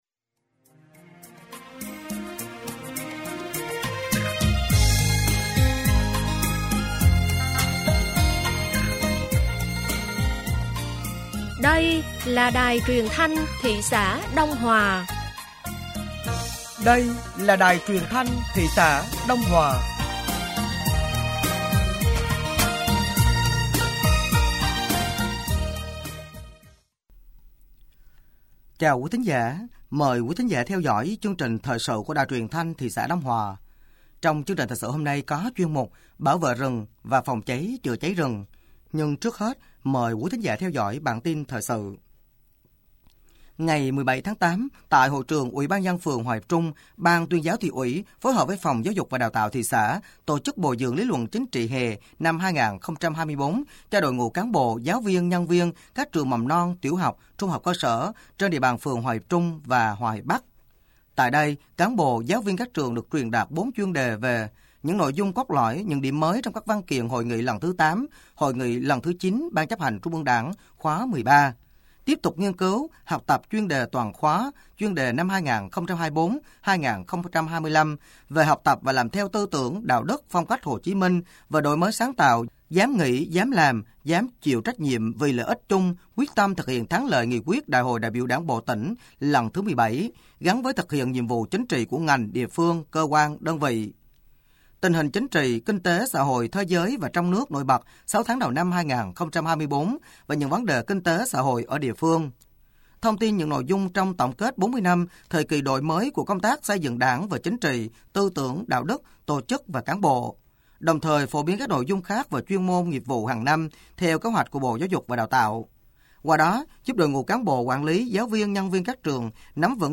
Thời sự tối ngày 17 và sáng ngày 18 tháng 8 năm 2024